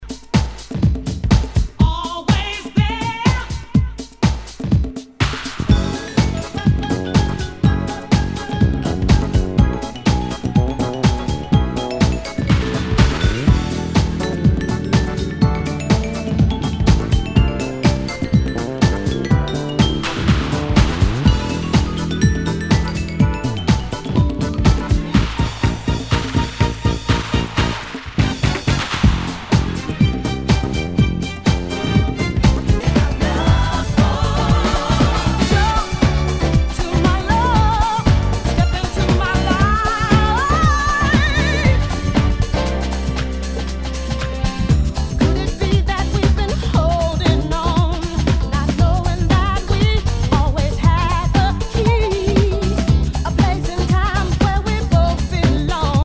HOUSE/TECHNO/ELECTRO
ナイス！ヴォーカル・ハウス！
盤に歪みあり。